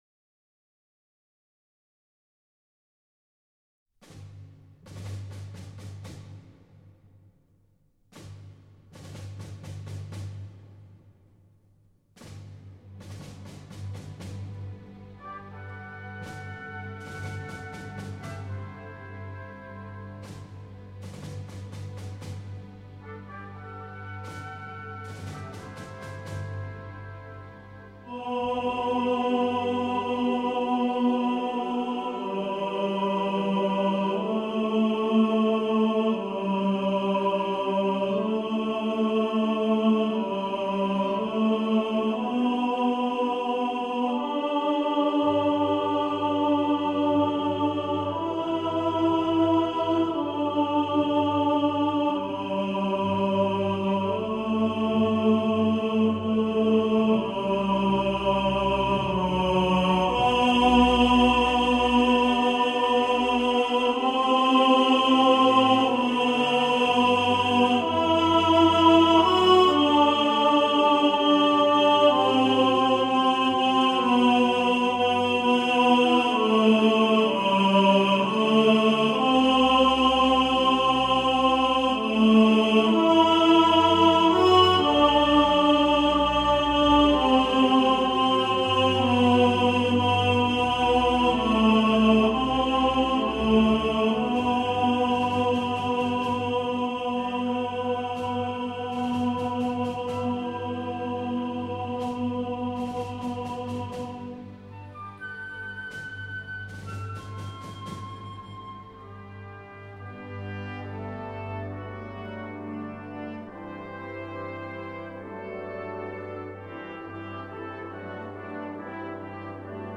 Hymn To The Fallen – Tenor | Ipswich Hospital Community Choir